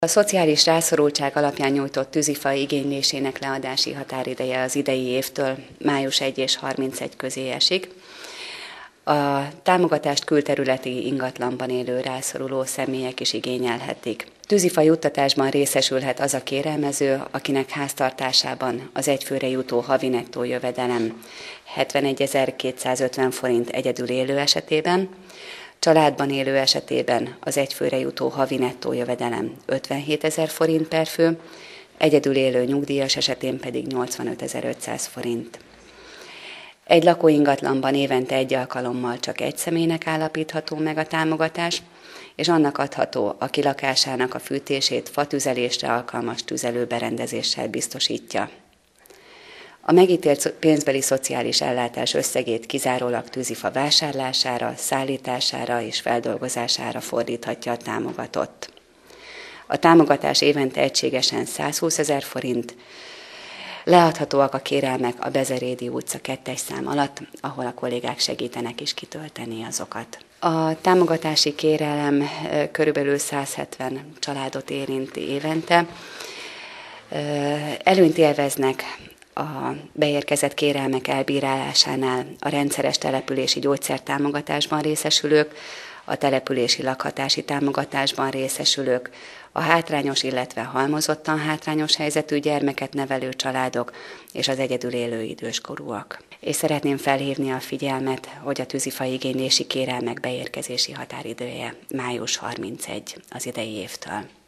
Május 31-ig lehet igényelni a tüzelőt.  A következő téli szezonnal kapcsolatos tűzifa igénylésről tartott sajtótájékoztatót a városházán Gréczy-Félegyházi Gyöngyvér, a Szociális, Egészségügyi, Környezeti és Fenntarthatósági Bizottság elnöke.